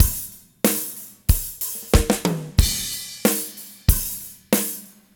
06 rhdrm93roll.wav